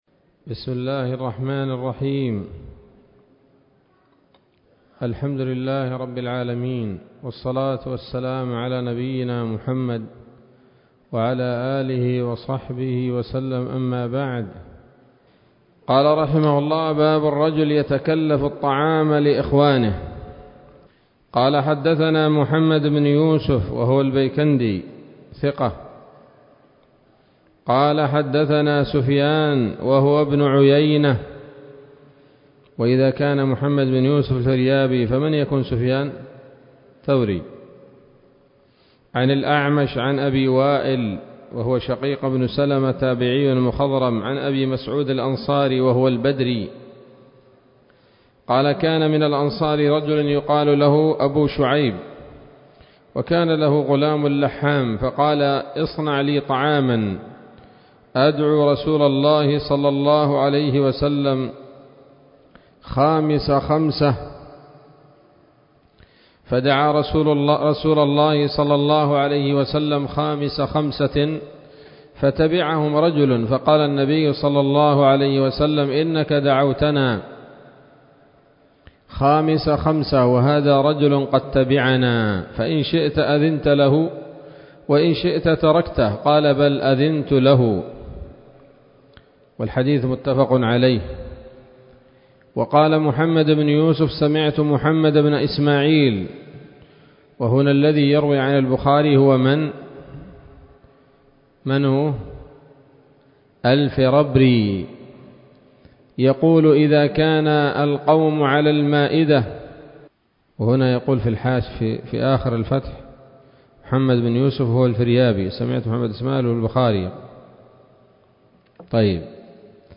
الدرس الحادي والعشرون من كتاب الأطعمة من صحيح الإمام البخاري